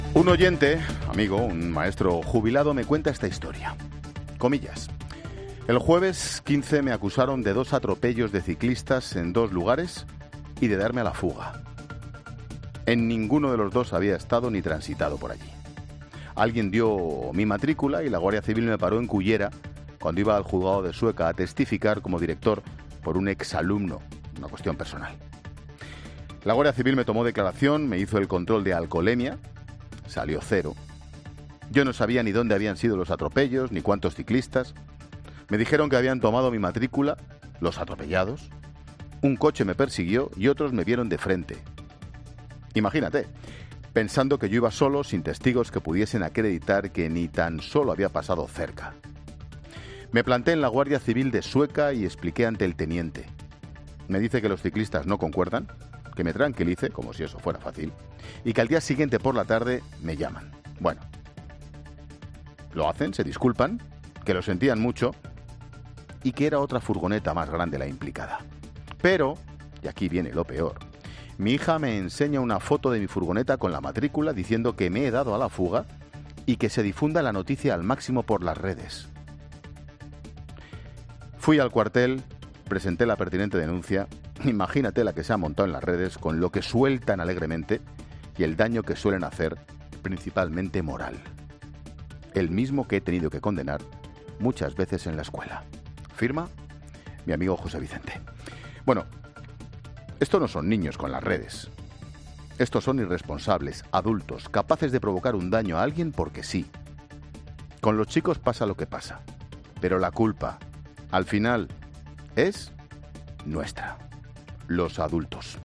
Monólogo de Expósito
Monólogo de Ángel Expósito a las 17h., en el que cuenta el caso de un oyente, linchado en la redes tras ser falsamente acusado por un atropello que no cometió.